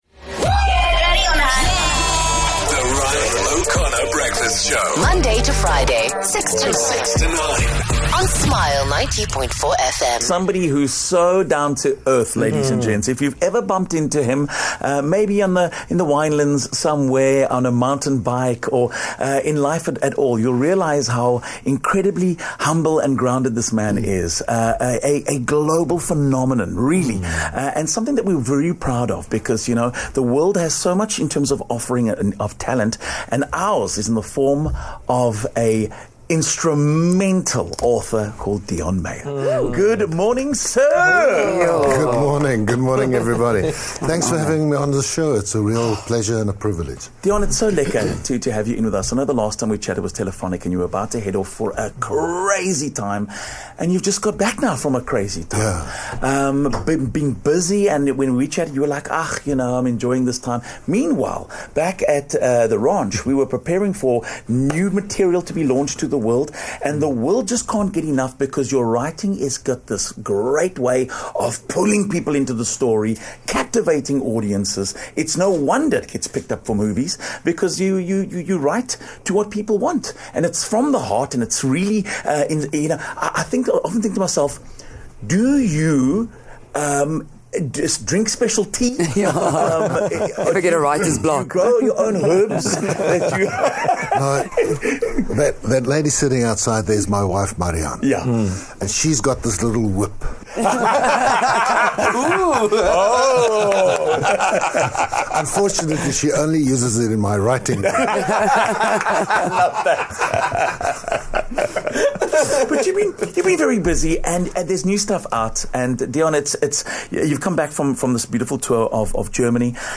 The English version of Deon Meyer’s book 'Leo' has recently hit the shelves. He popped into studio for chat.